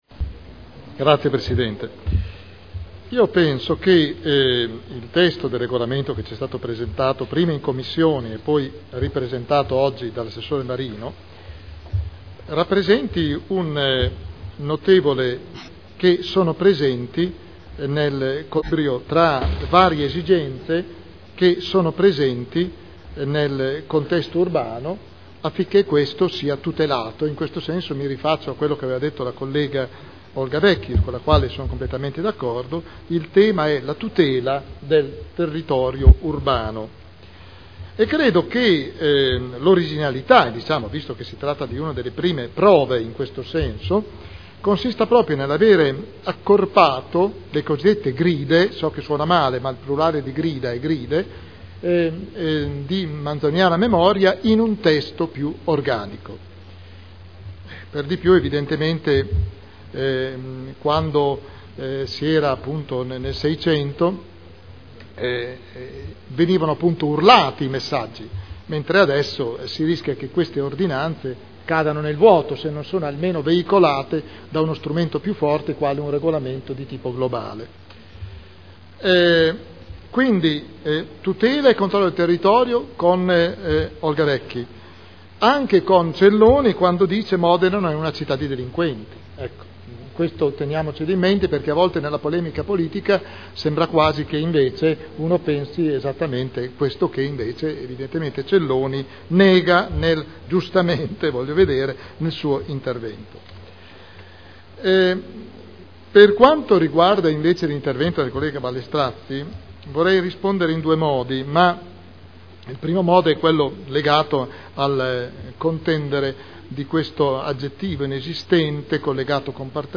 William Garagnani — Sito Audio Consiglio Comunale
Modifiche al Regolamento di Polizia Urbana approvato con deliberazione del Consiglio comunale n. 13 dell’11.2.2002 Dibattito